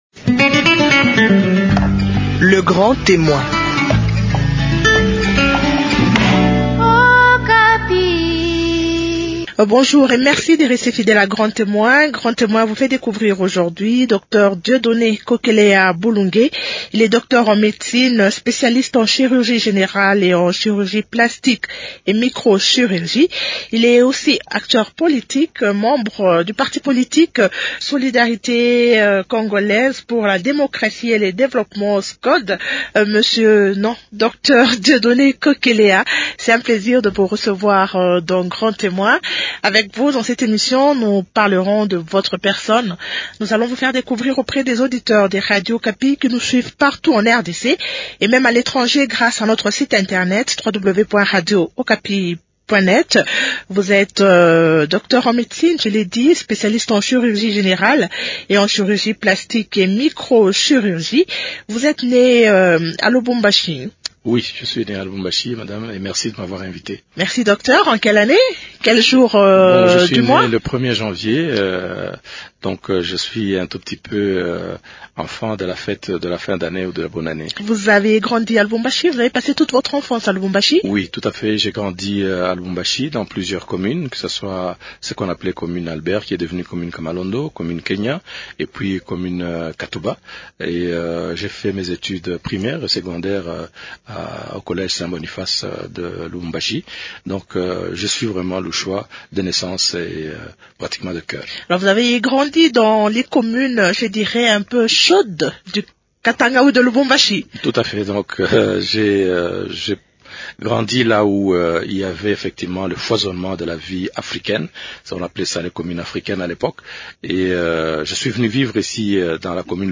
Il déplore aussi le peu de moyens alloués au secteur de santé en RDC. En homme politique, il donne son point de vue sur les pourparlers de Kampala entre le gouvernement de la RDC et la rébéllion du M23 . Découvrez aussi dans cet entretien sa passion pour la rumba congolaise.